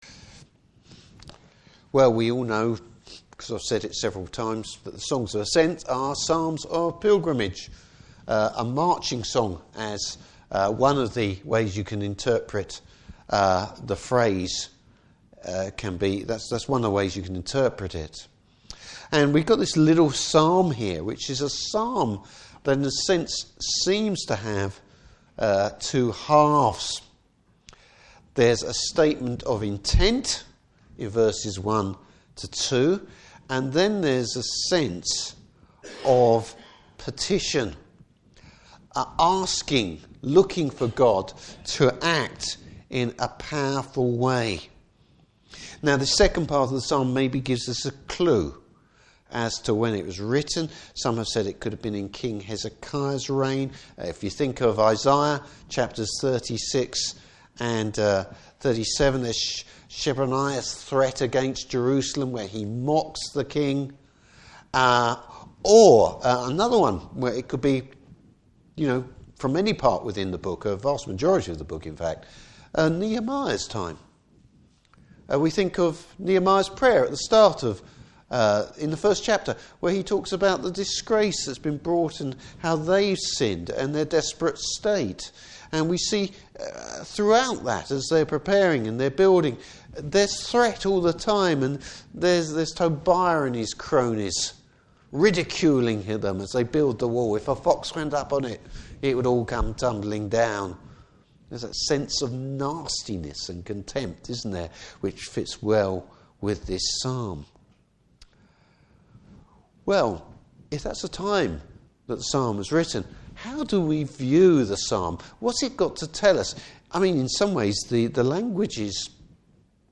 Service Type: Evening Service Having confidence in God in difficult times.